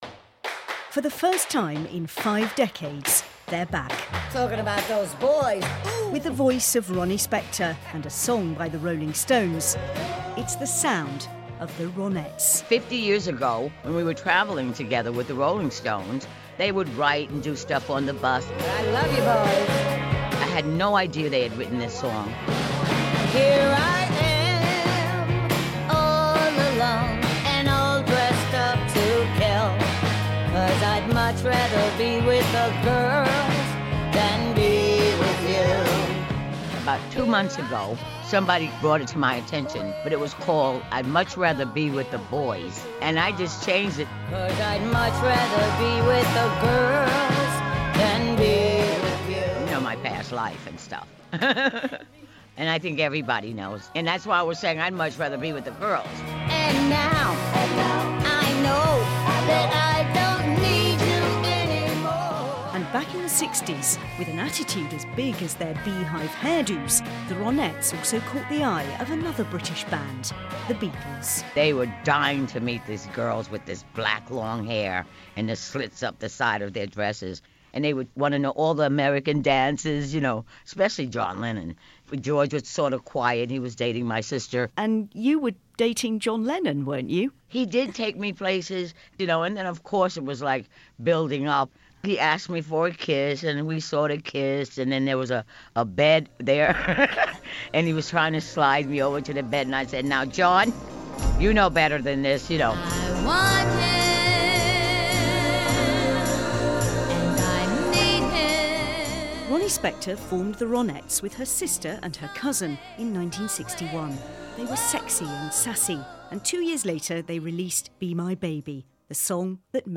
Lead singer of The Ronettes, Ronnie Spector talks about her career ahead of a series of Ronettes tribute concerts in Britain. Includes mentions of the Rolling Stones and John Lennon.